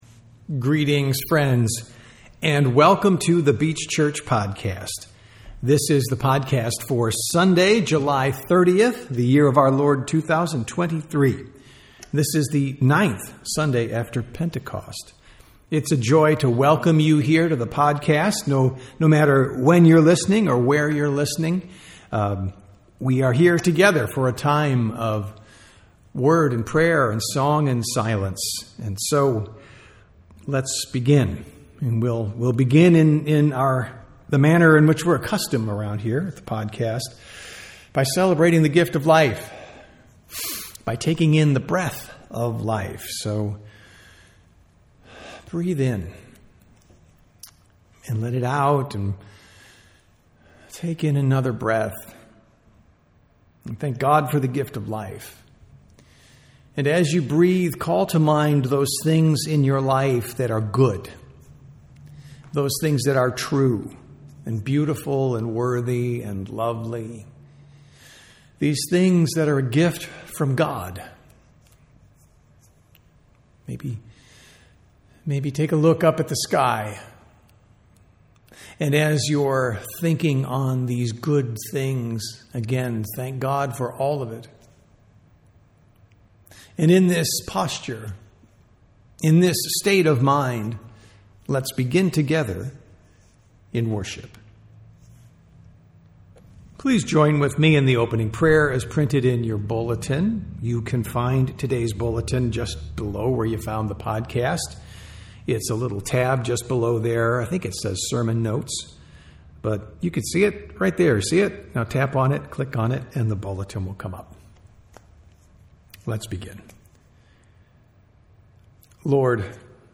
Sunday Worship - July 30, 2023